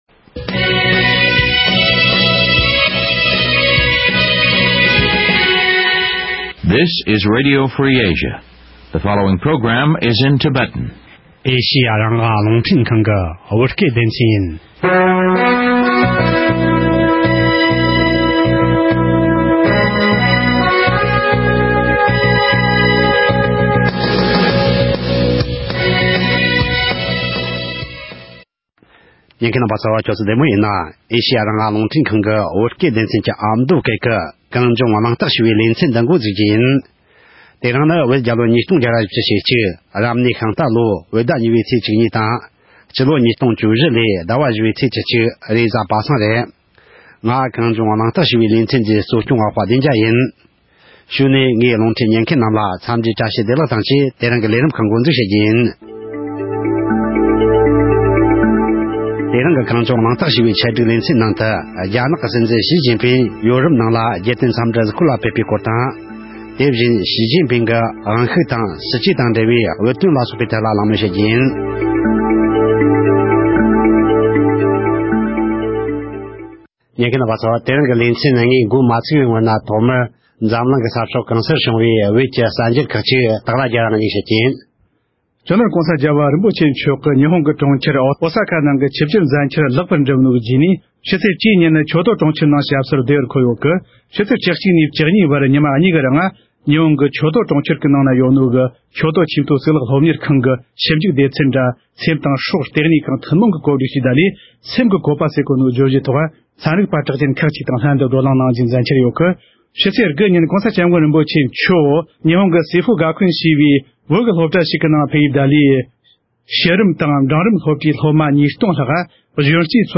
གླེང་མོལ་ཞུས་པར་གསན་རོགས་གནང་།།